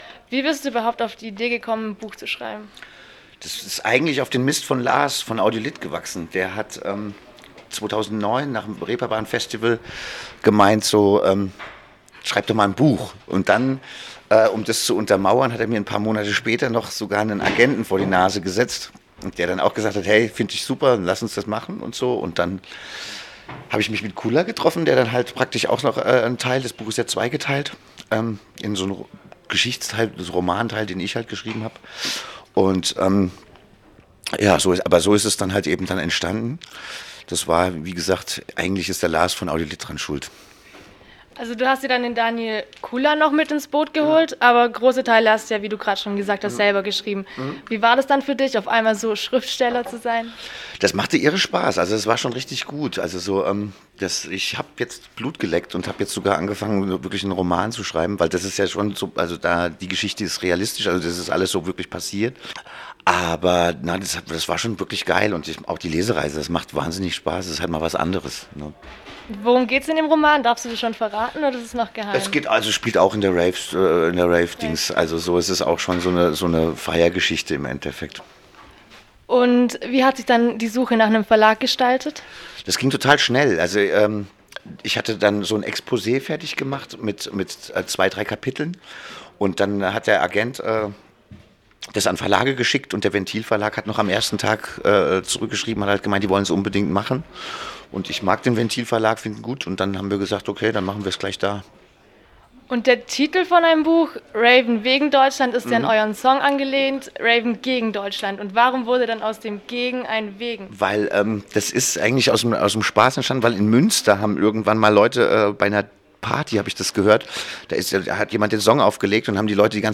"Raven wegen Deutschland" - Interview